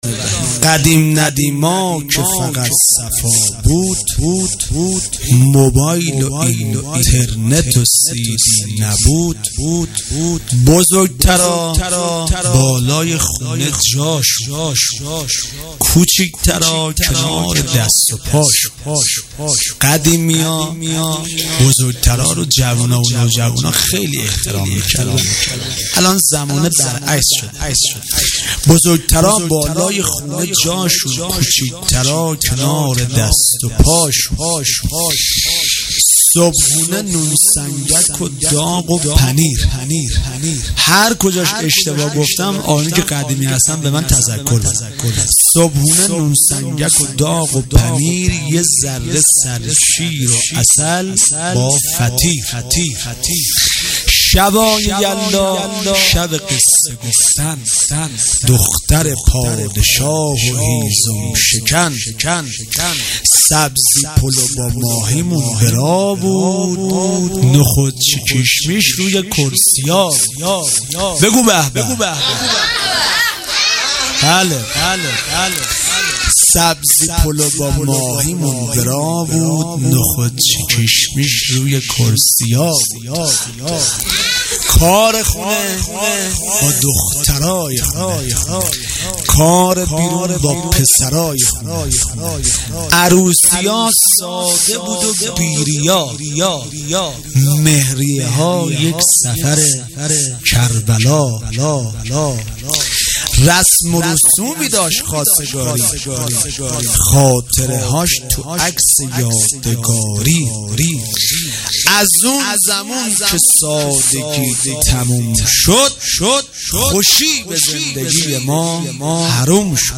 میلاد حضرت زینب (س) 98